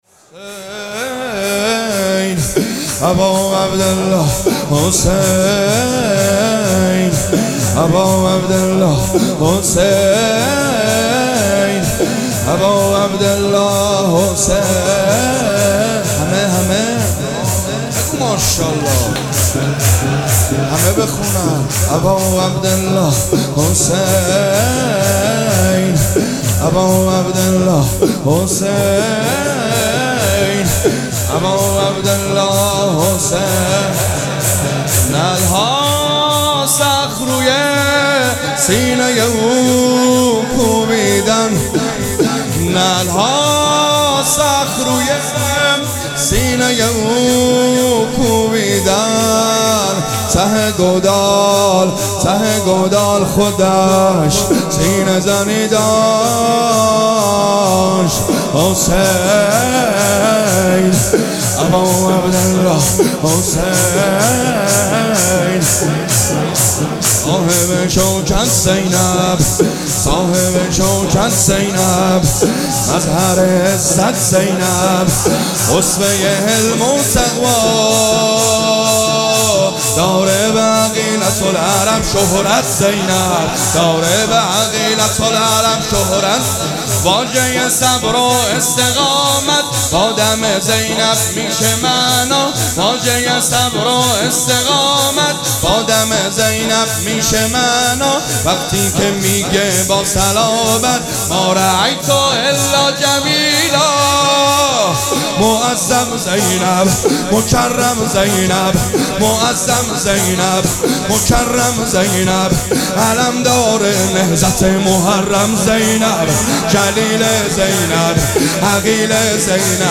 مراسم عزاداری شام شهادت حضرت رقیه سلام الله علیها
شور